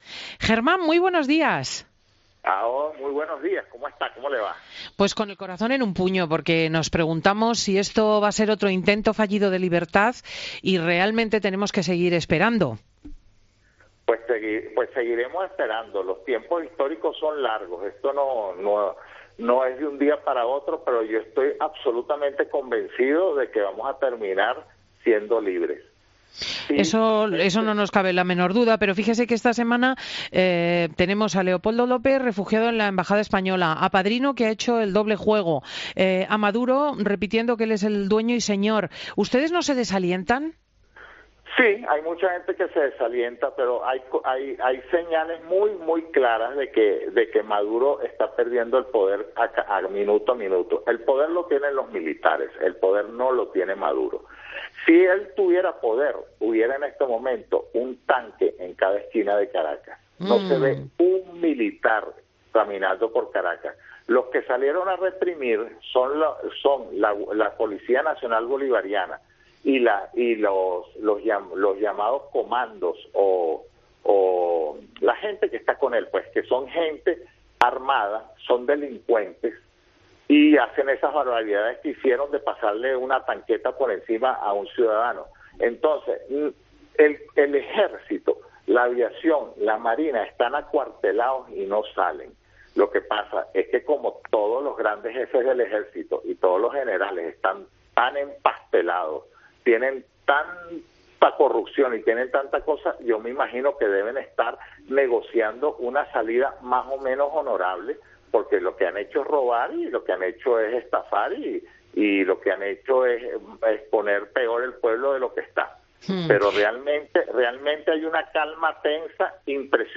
Hablamos con un ciudadano venezolano sobre la situación en el país